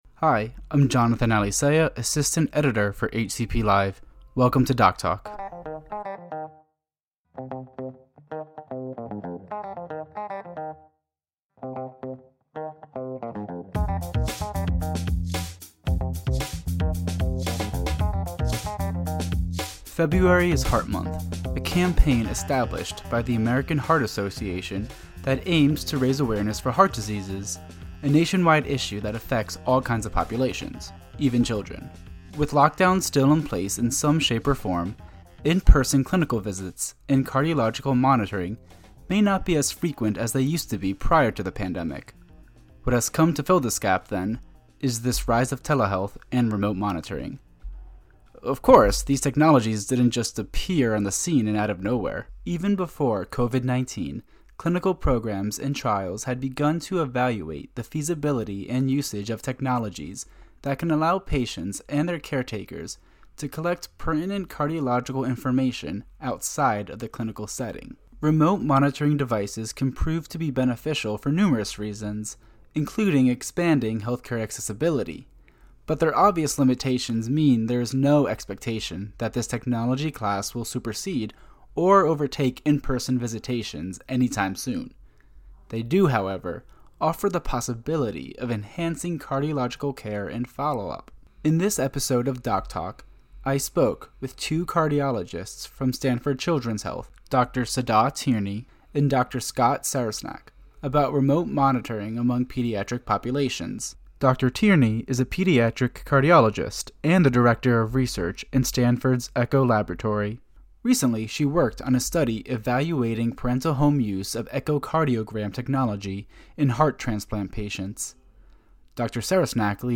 In a joint interview, 2 pediatric cardiologists discuss topics related to remote technologies and cardiological monitoring among pediatric populations.